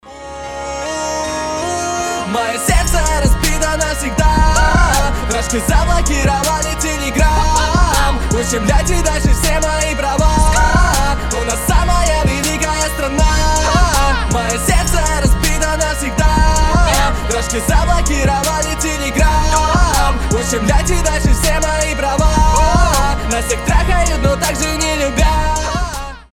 • Качество: 320, Stereo
громкие
Cover
кавер
пародия